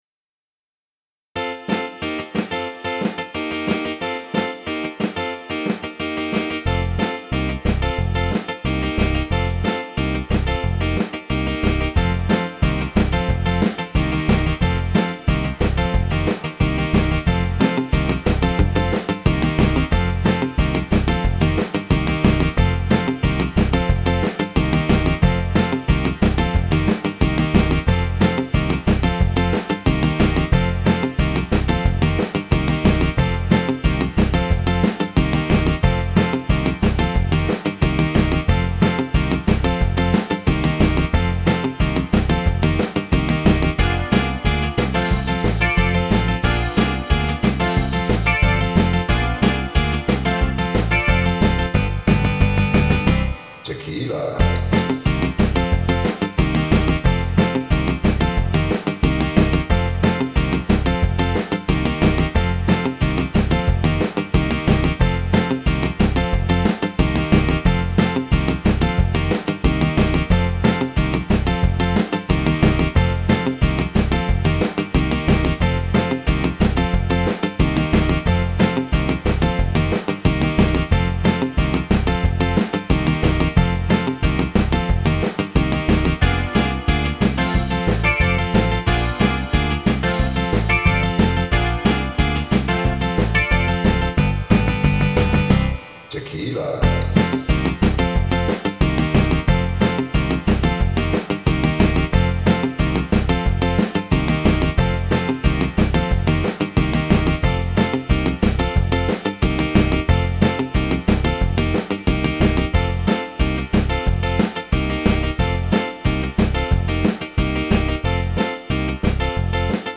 Englewood Market March 20 2025
Tequila (Live Performance).m4a